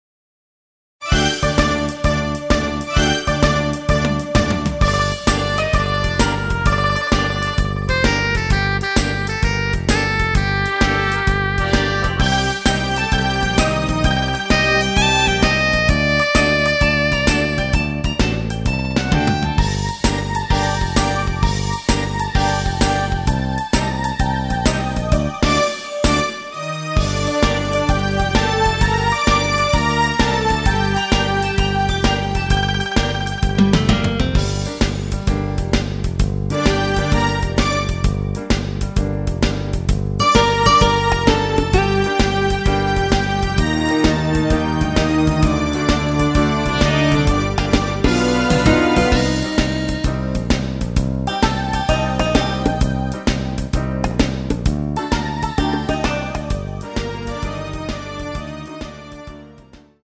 MR입니다.
Gm
앞부분30초, 뒷부분30초씩 편집해서 올려 드리고 있습니다.
중간에 음이 끈어지고 다시 나오는 이유는